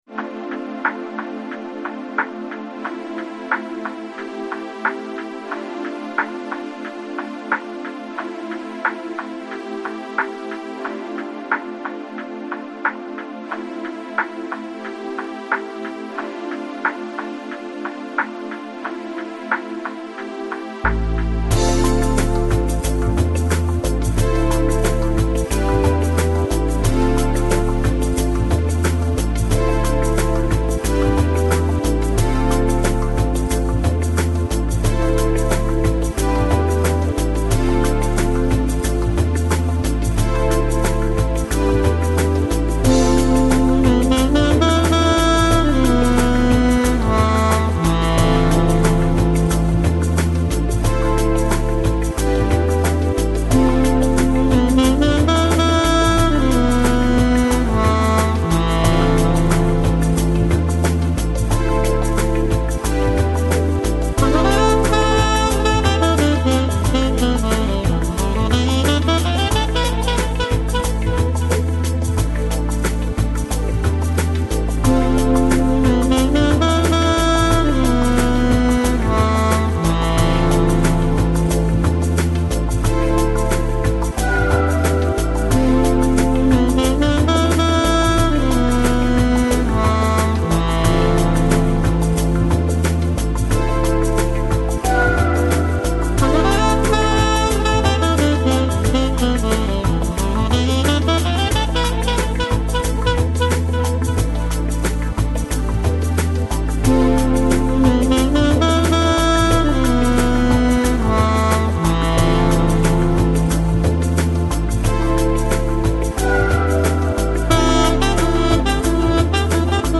Жанр: Downtempo, Lounge, Chillout, Ambient